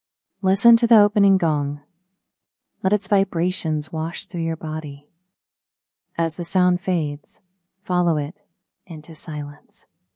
Gong & Bowl Meditation — 15 Minutes | ILMA
Minimal voice guidance with regular singing bowl and gong sounds. Let the resonant tones guide your awareness deeper with each strike.